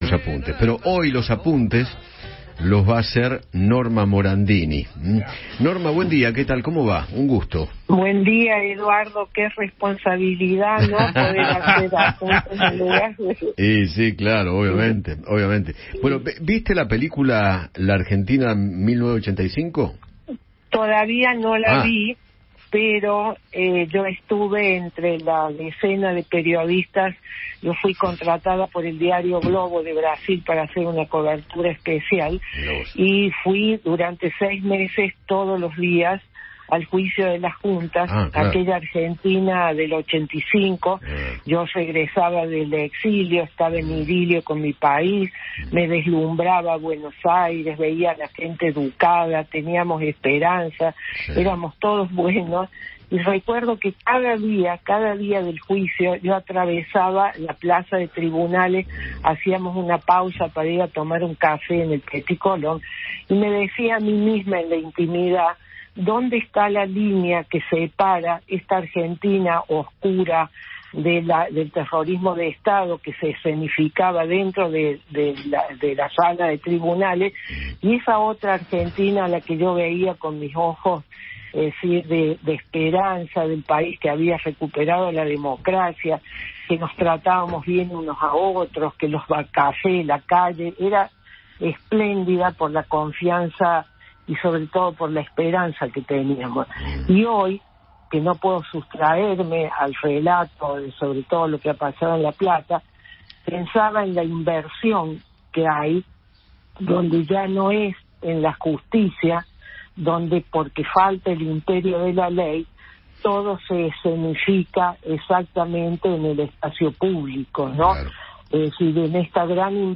Norma Morandini, periodista y escritora, conversó con Eduardo Feinmann sobre la película “Argentina, 1985” y recordó cómo se vivió aquel año en el país.